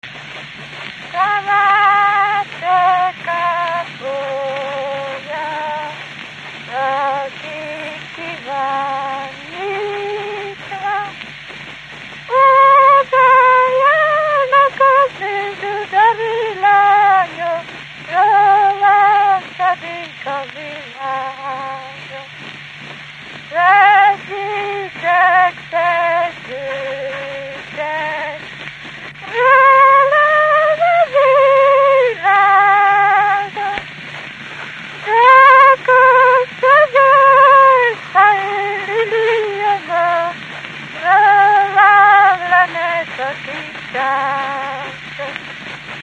Dunántúl - Veszprém vm. - Dudar
Stílus: 9. Emelkedő nagyambitusú dallamok
Kadencia: 2 (5) 3 1